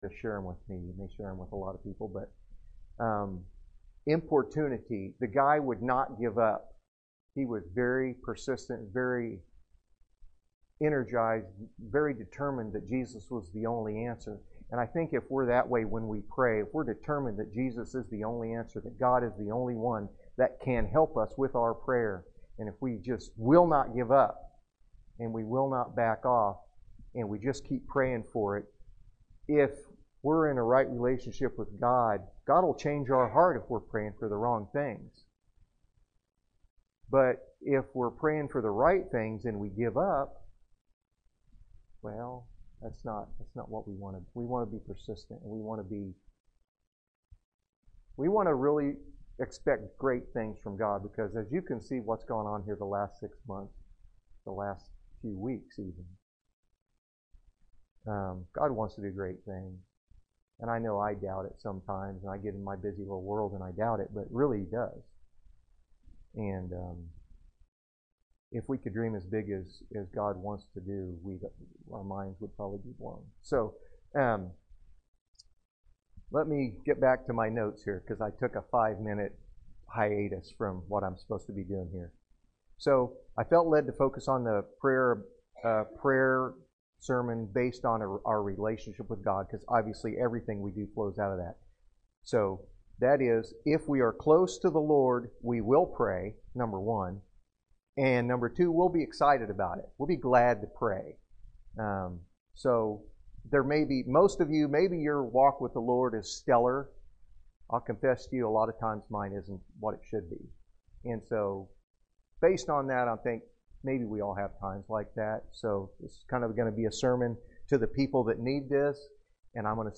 Special Prayer Service – Valley Avenue Baptist Church – Falls City, NE
Special Prayer Service